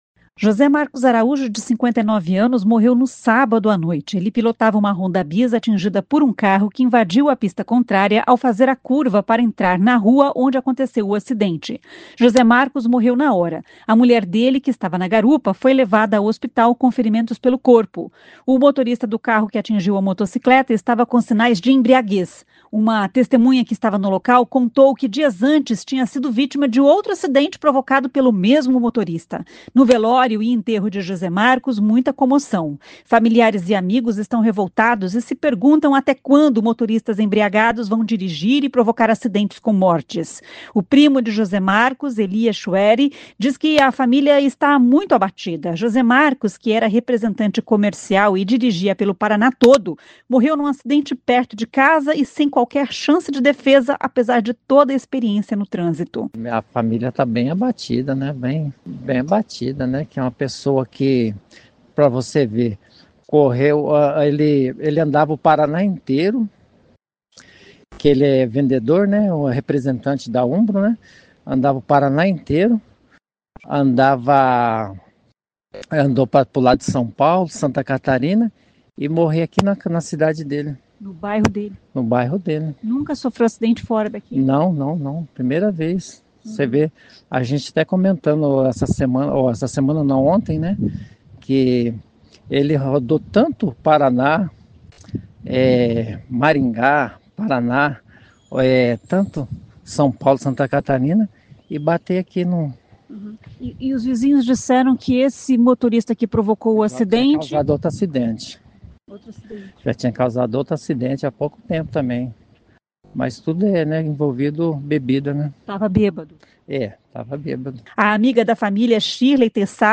Parentes e amigos emocionados falaram da dor e da revolta.